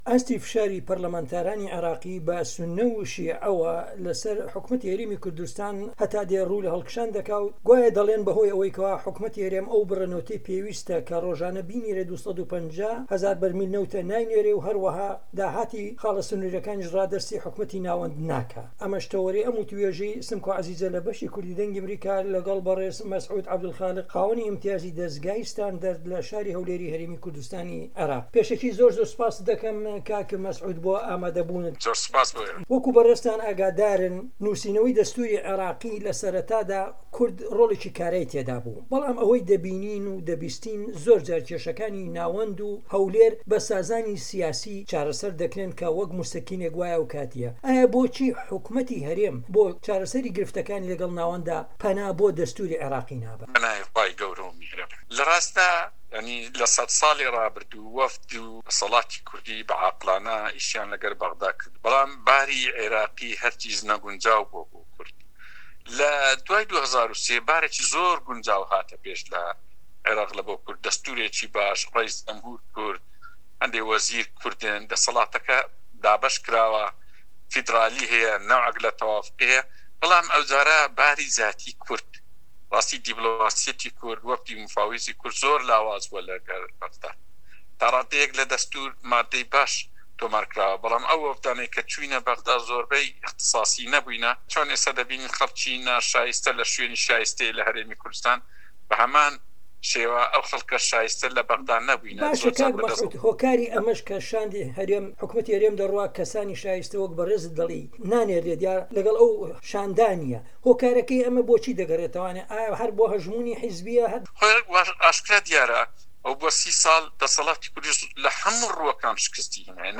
دەقی وتووێژەکە